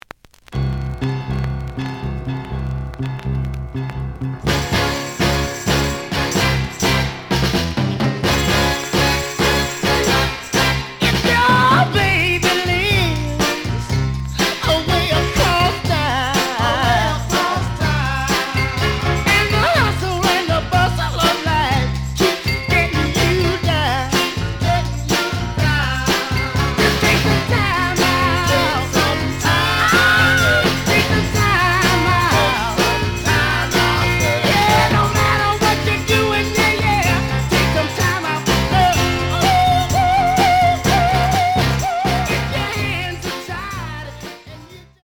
試聴は実際のレコードから録音しています。
●Genre: Soul, 60's Soul